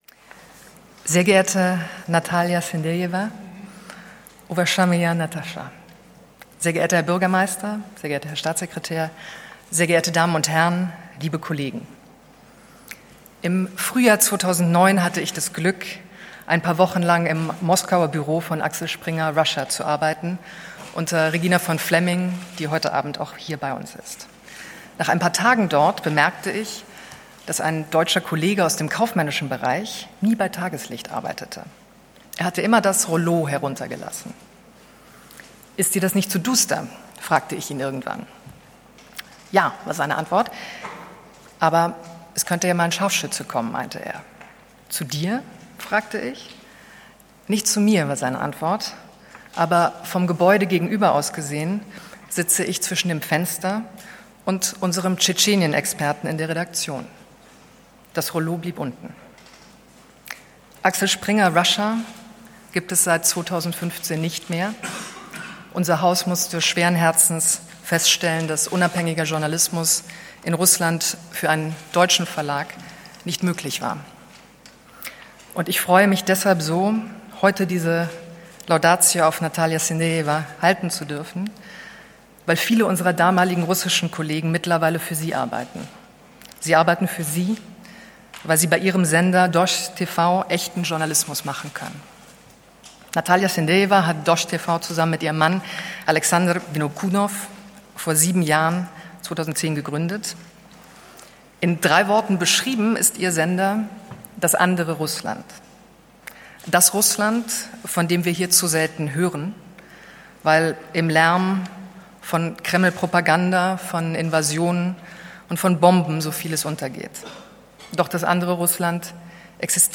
Was: Laudatio Verleihung M100-Sanssouci Medien Preis 2017
Wo Orangerie Schloss Sanssouci, Potsdam